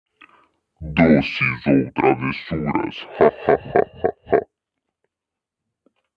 audio_halloween_caveira.wav